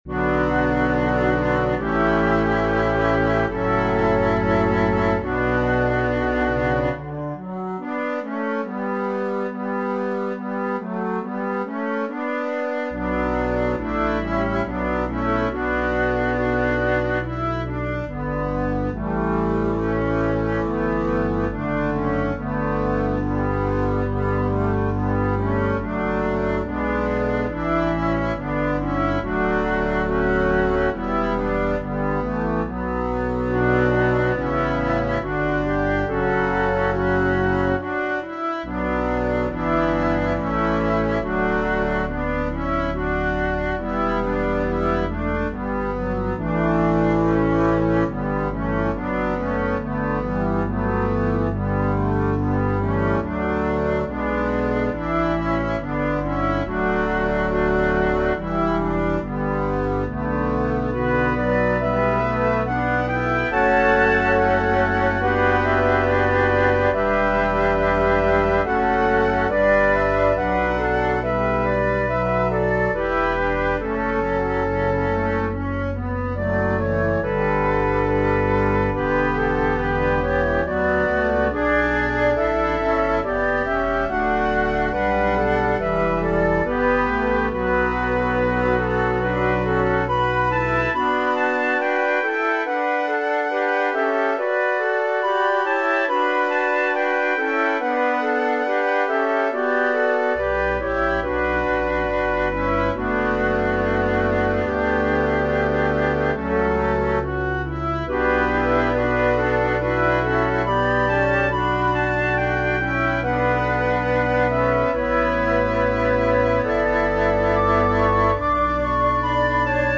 Voicing/Instrumentation: Organ/Organ Accompaniment We also have other 43 arrangements of " If You Could Hie to Kolob ".